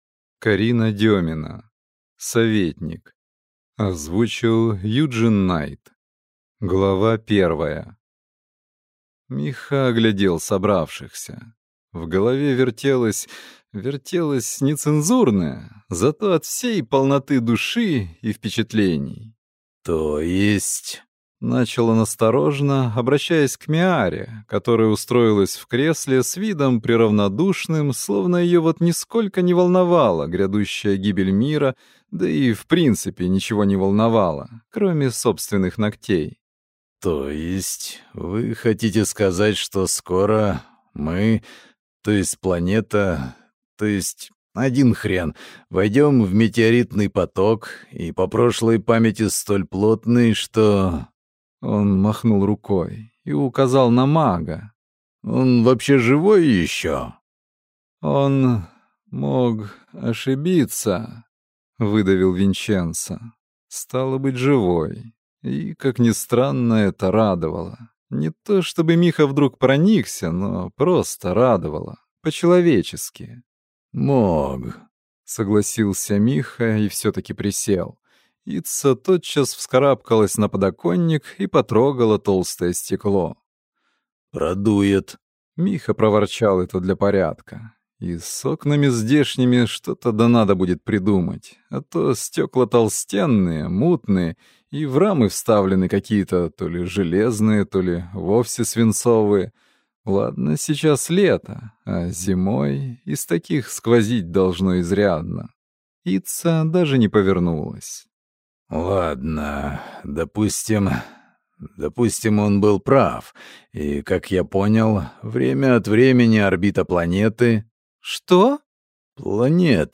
Аудиокнига Советник | Библиотека аудиокниг